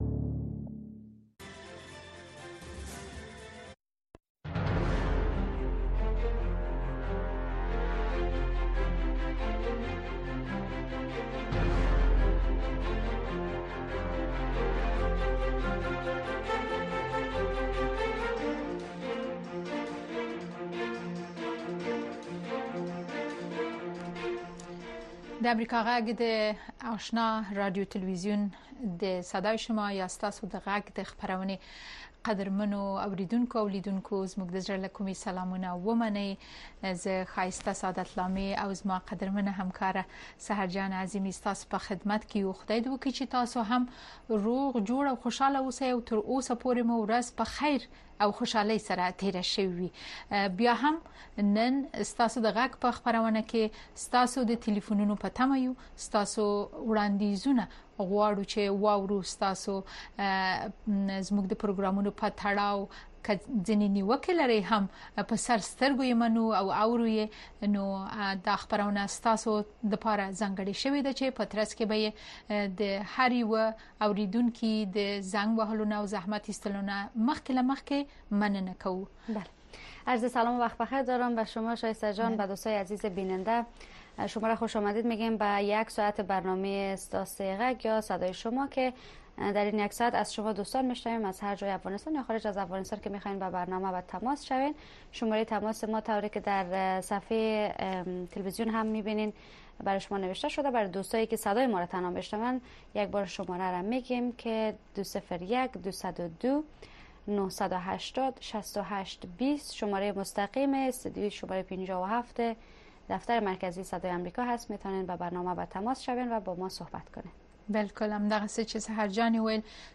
این برنامه به گونۀ زنده از ساعت ۹:۳۰ تا ۱۰:۳۰ شب به وقت افغانستان نشر می‌شود.